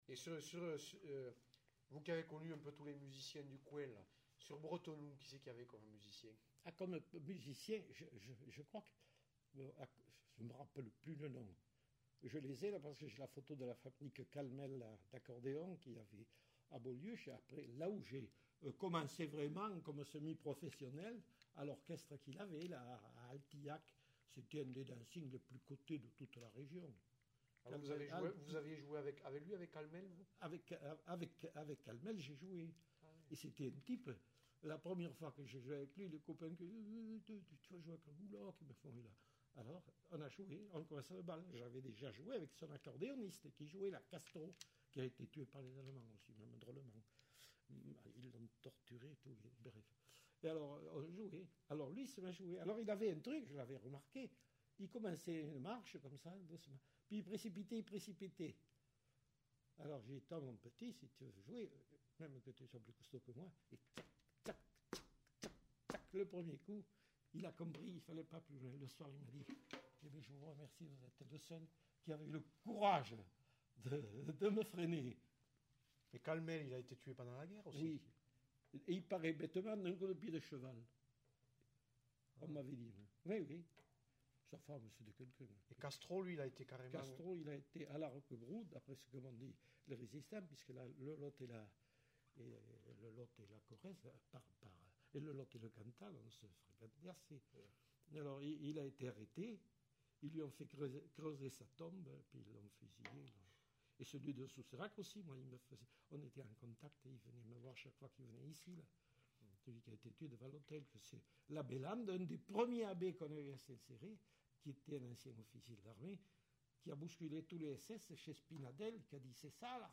Aire culturelle : Quercy
Lieu : Saint-Céré
Genre : témoignage thématique